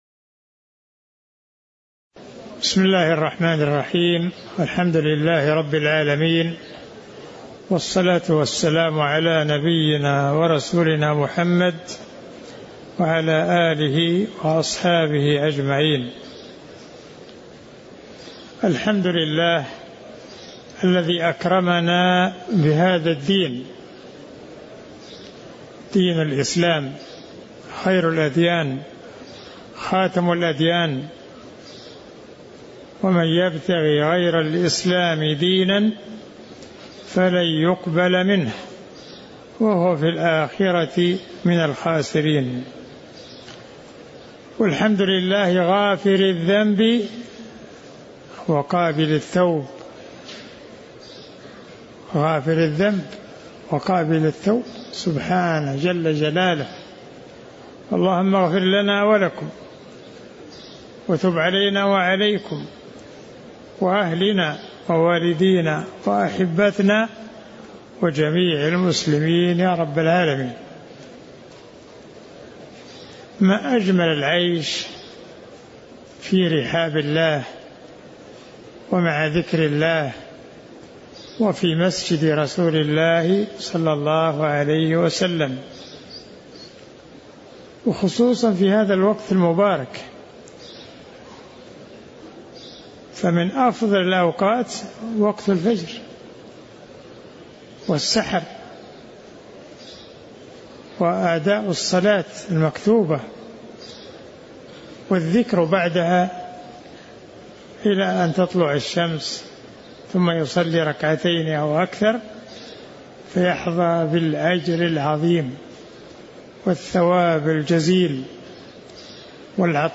تاريخ النشر ١٠ ربيع الأول ١٤٤٦ هـ المكان: المسجد النبوي الشيخ